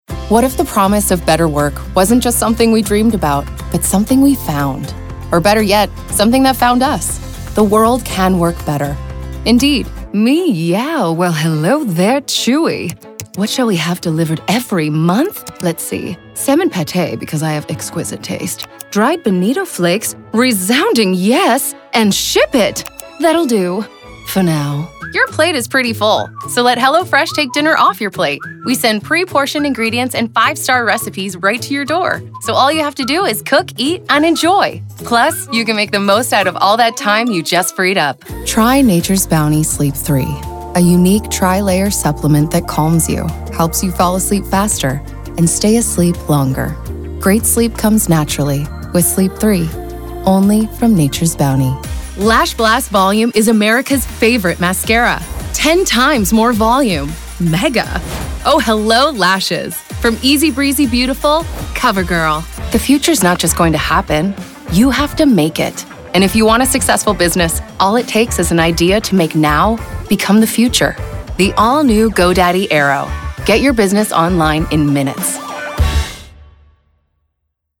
The versatile voice you can feel
Commercial Demo
trans-atlantic, New Jersey, valley-girl,